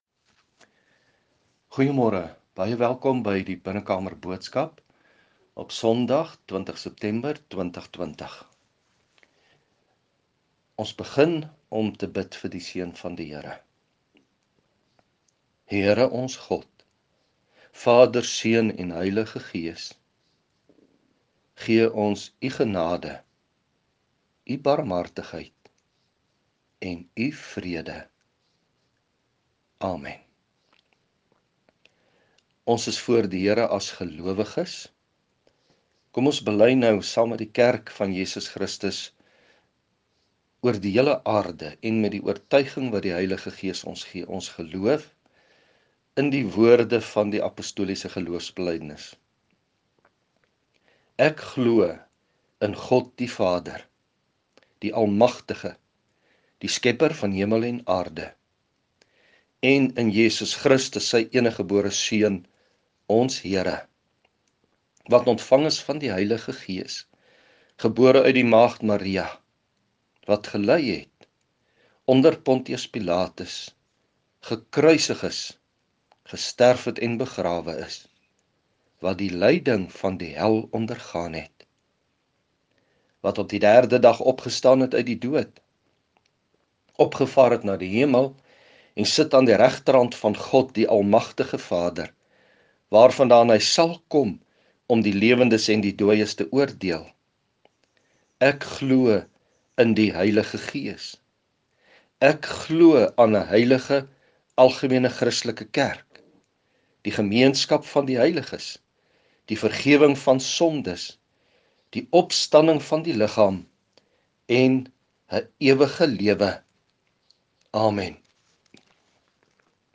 Klankbaan